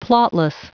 Prononciation du mot plotless en anglais (fichier audio)
Prononciation du mot : plotless